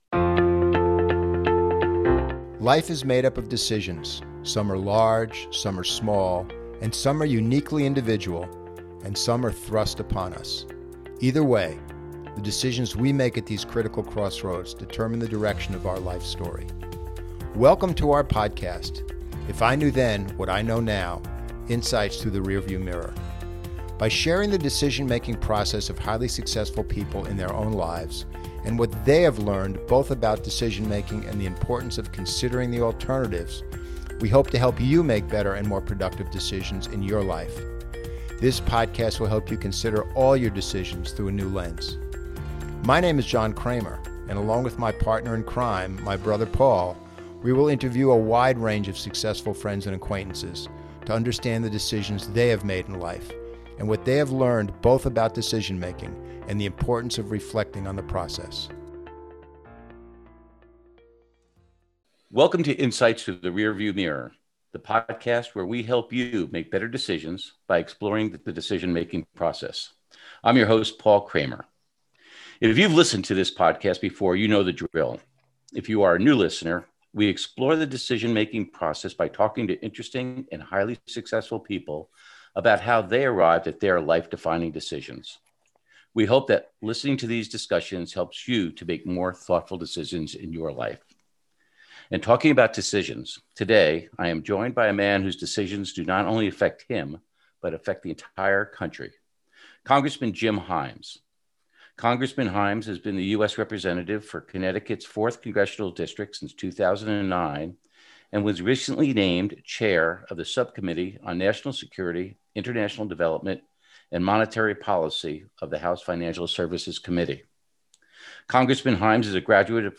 Guest, Jim Himes, The Importance of Empathy When Making Any Decision